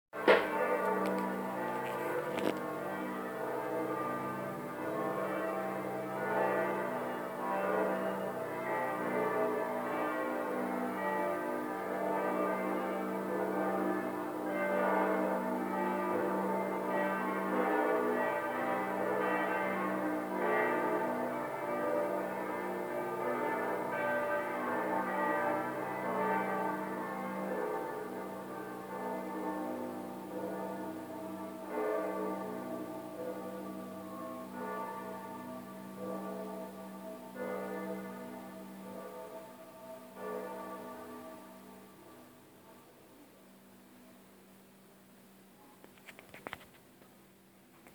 I watched the sun come up with it’s delicious fall coloring and the hush of the neighborhood.
I think it’s only fitting that I upload them for you as they are gorgeous. This was taken from our terrace.
Church Bells – Duomo      (Right click for PC)